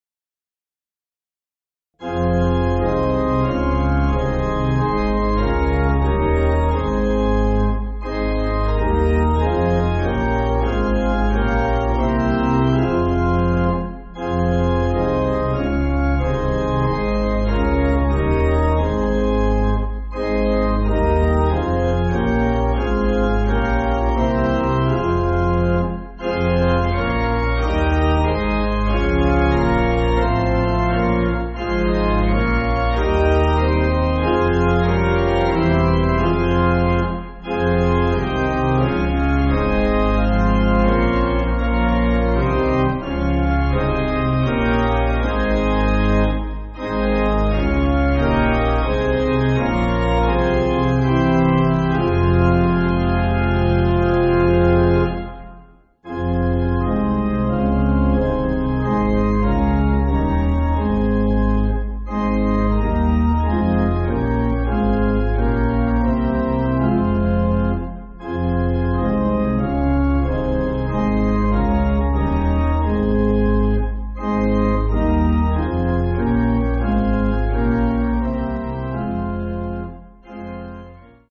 Organ
(CM)   4/Fm